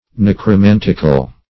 Necromantic \Nec`ro*man"tic\, Necromantical \Nec`ro*man"tic*al\,